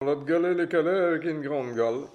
Mots Clé fruit(s), confiture ; Localisation Xanton-Chassenon
Catégorie Locution